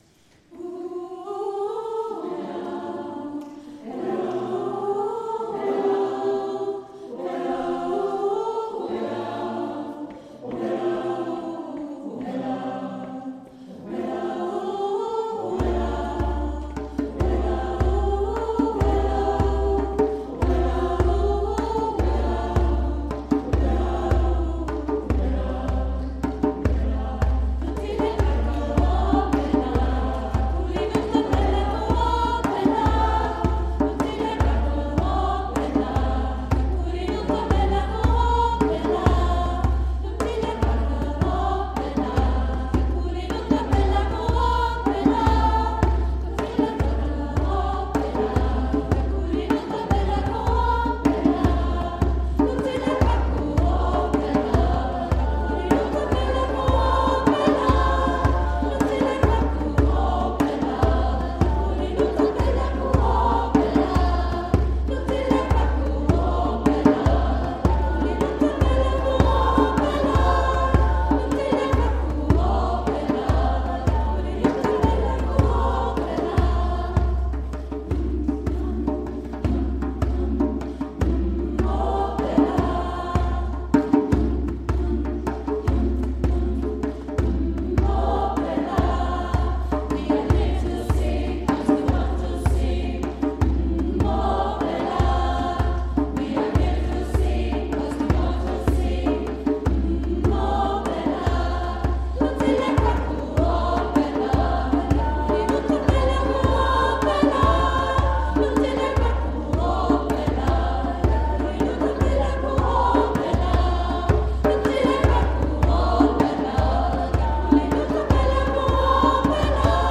Die afrikanischen Lieder aus dem Gottesdienst